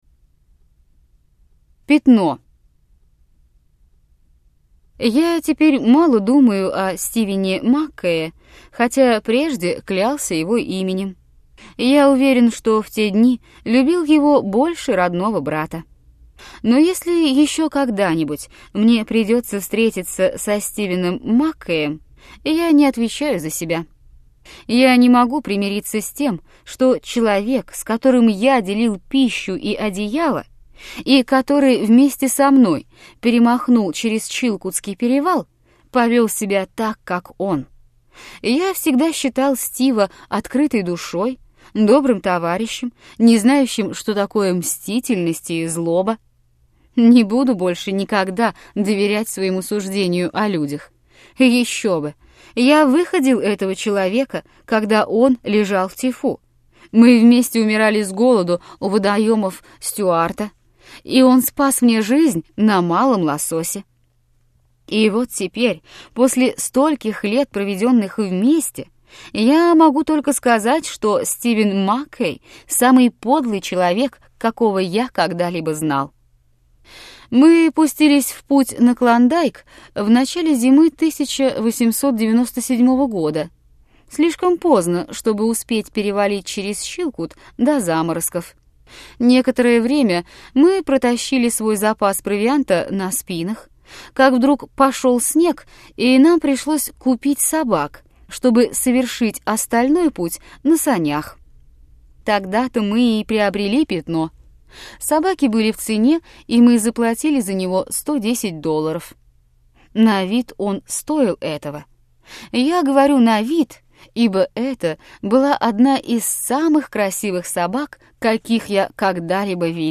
Аудиокнига Вера в человека. Потерянный Лик. Вечные формы и другие рассказы | Библиотека аудиокниг